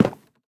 Minecraft Version Minecraft Version latest Latest Release | Latest Snapshot latest / assets / minecraft / sounds / block / cherrywood_fence_gate / toggle1.ogg Compare With Compare With Latest Release | Latest Snapshot
toggle1.ogg